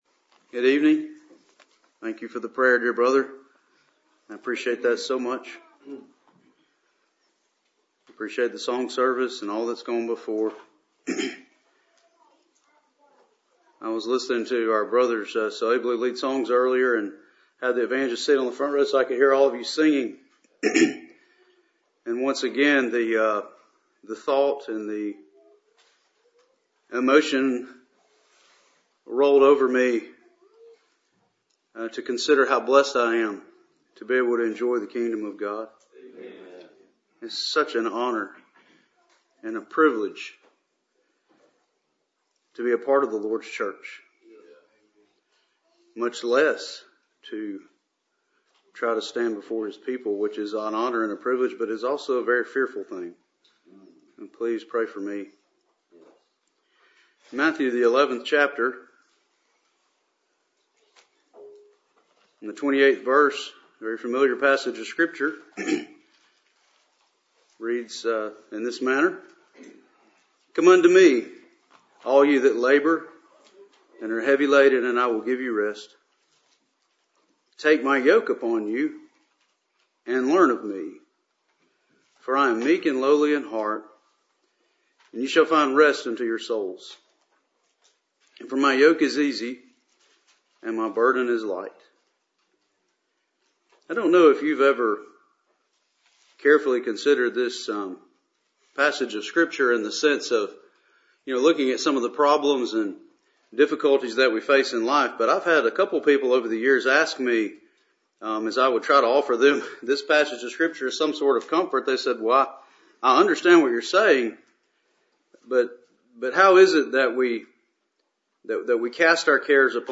Passage: Matthew 11:28-30, Matthew 15:29-38 Service Type: Cool Springs PBC Sunday Evening